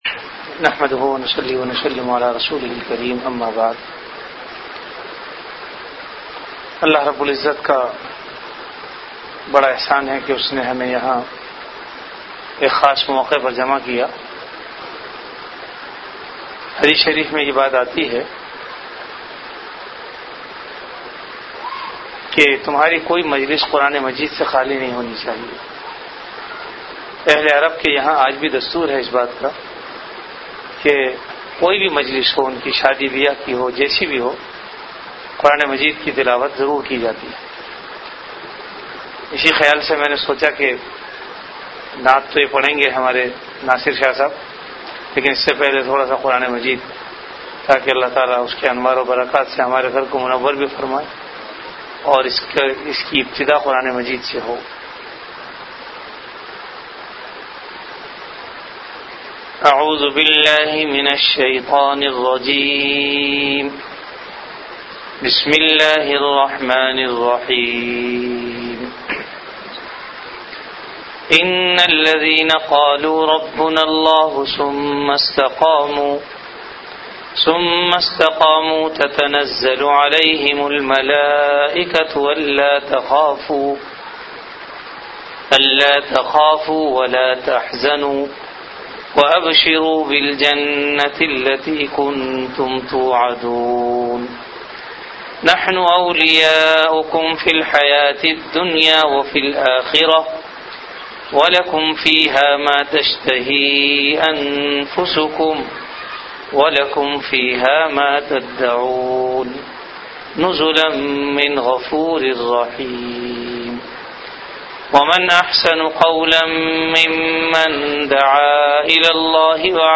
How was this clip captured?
After Isha Prayer